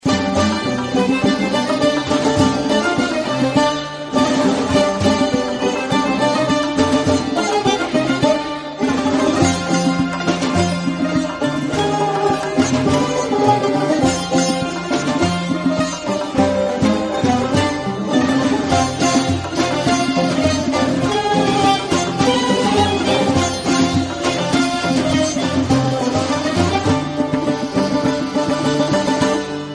رینگتونشاد بی کلام